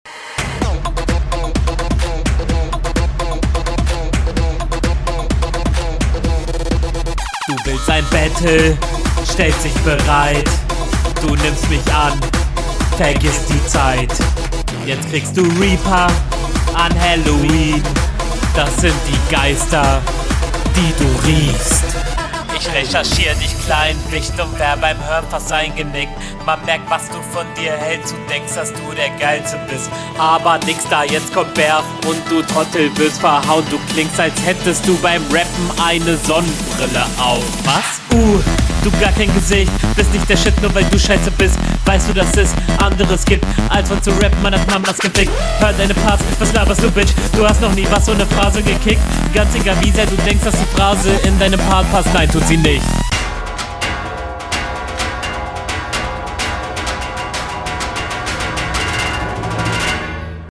Ich mag den Aufbau am Anfang bevor es richtig losgeht, hätte mir da aber nen …
Urgh was soll dieser Beat.